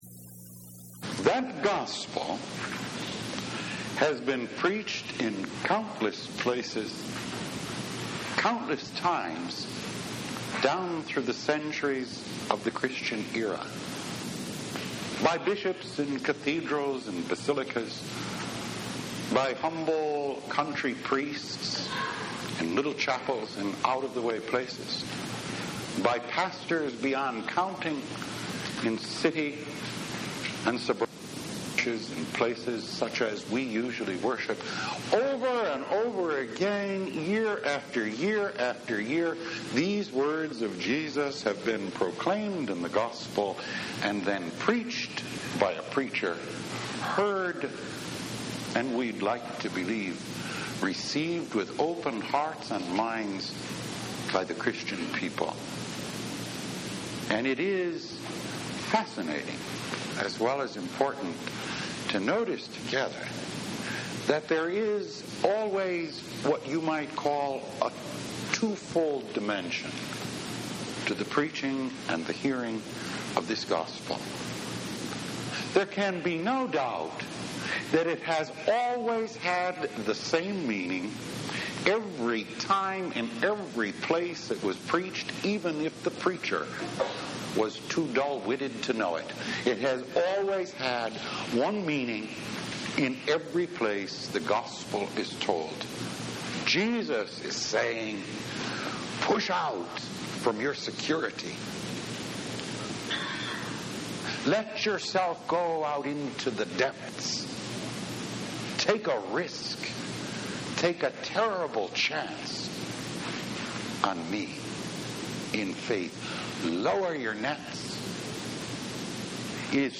Good News « Weekly Homilies